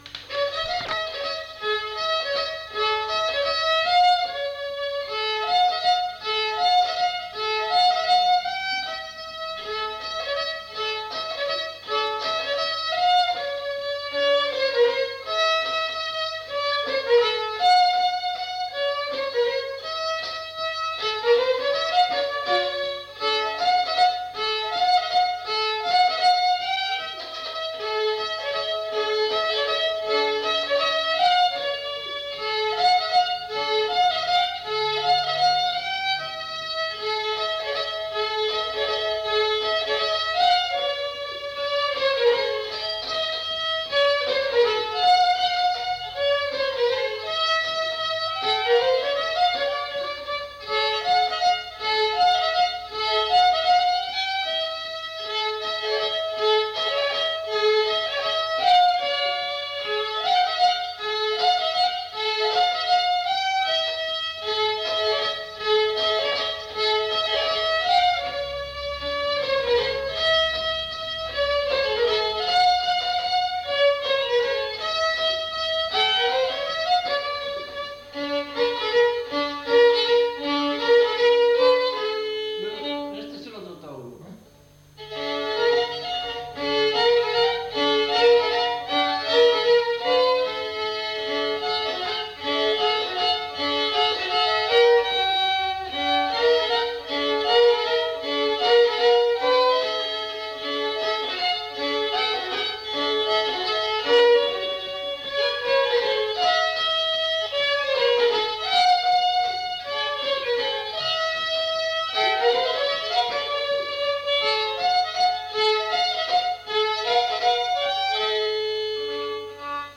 Aire culturelle : Petites-Landes
Lieu : Roquefort
Genre : morceau instrumental
Instrument de musique : violon
Danse : varsovienne
Notes consultables : 2 violons.